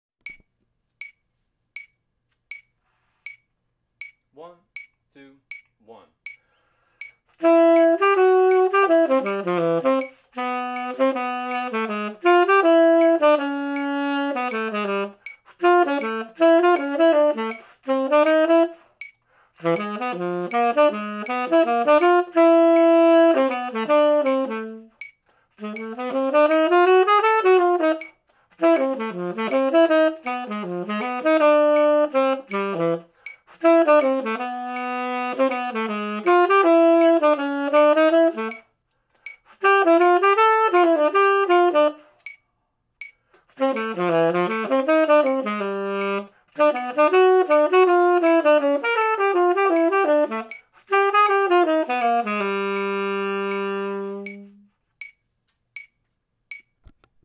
tenor trombone – etudes – 2018-2019
this audio practice-with-me track covers all of 2019 TMEA All-State Jazz Tenor Trombone Etude 1, bpm=160. this is the Region Cut.